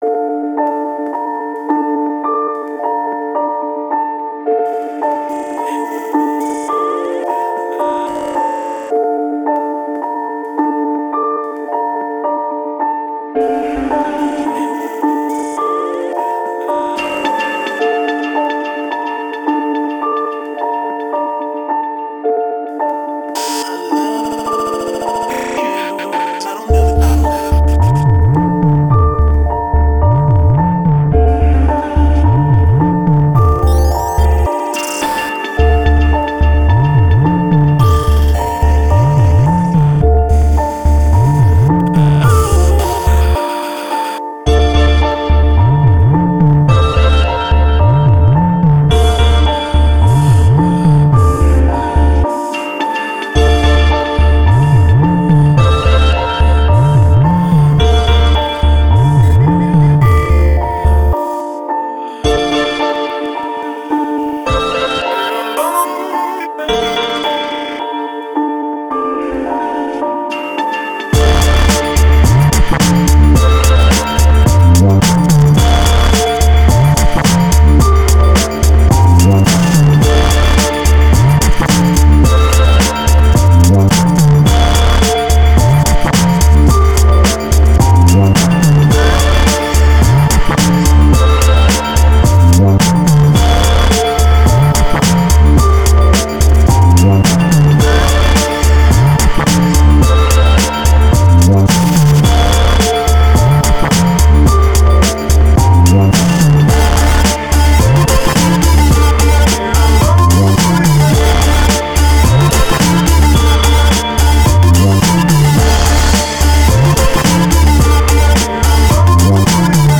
Very relaxing, Keep them coming!